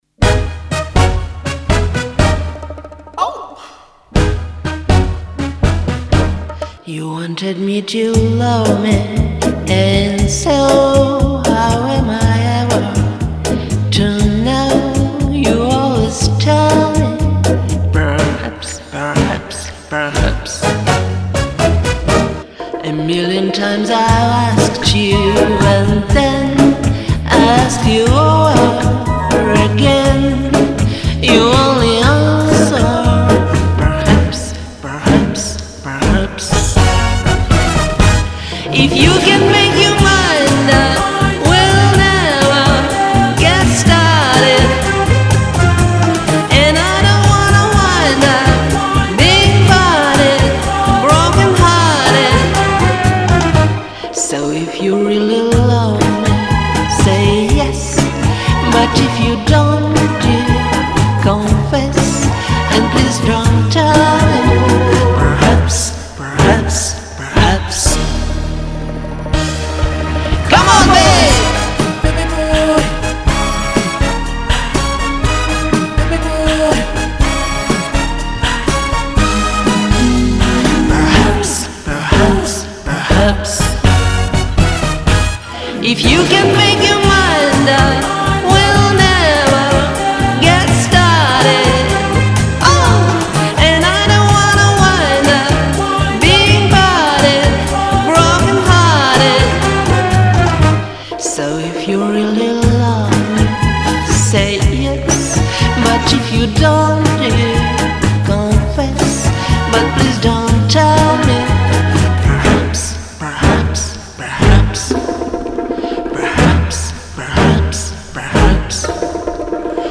множество, женские версии тоже есть